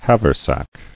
[hav·er·sack]